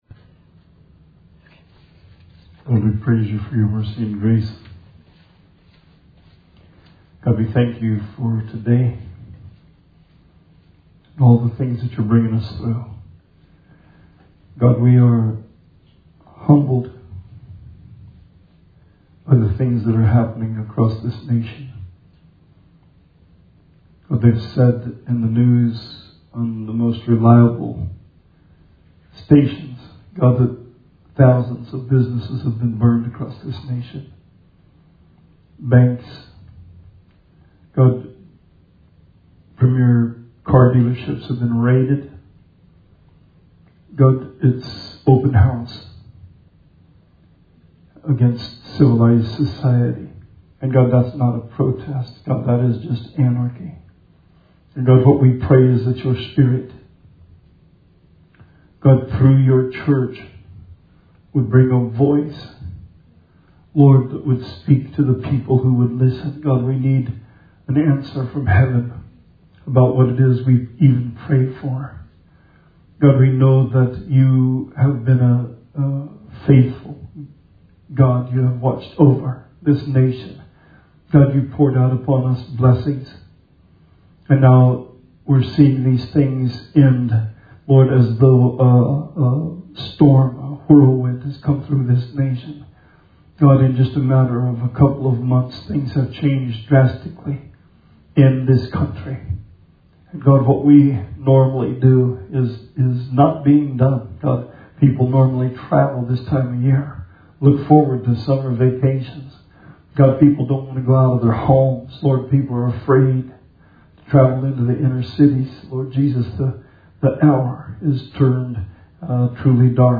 Sermon 6/7/20